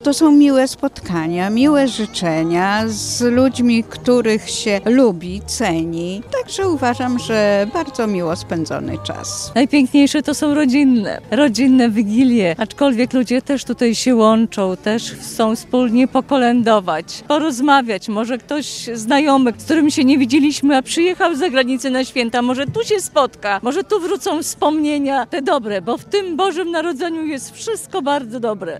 Było przekazanie Betlejemskiego Światełka Pokoju, dzielenie się opłatkiem i wspólne kolędowanie – mieszkańcy Puław spotkali się na Miejskiej Wigilii.
Miejską Wigilię na placu Fryderyka Chopina w Puławach zorganizowano już po raz 12.